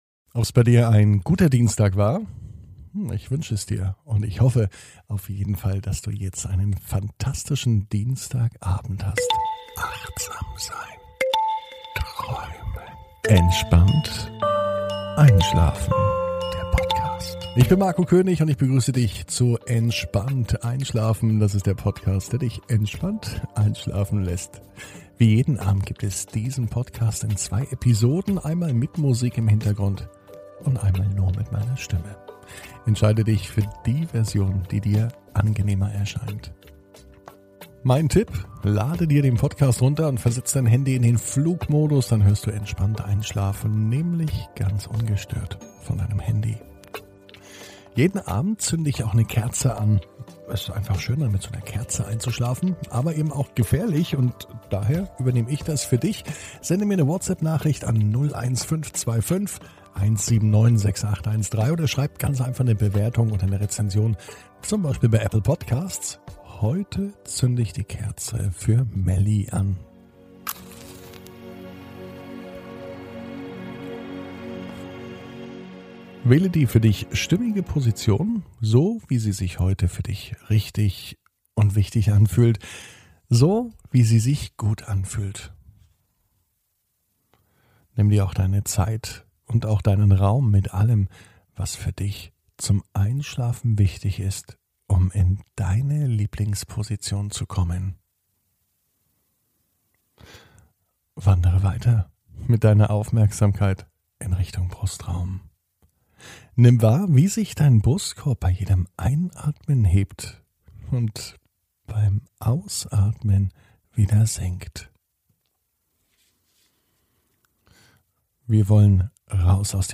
(ohne Musik) Entspannt einschlafen am Dienstag, 15.06.21 ~ Entspannt einschlafen - Meditation & Achtsamkeit für die Nacht Podcast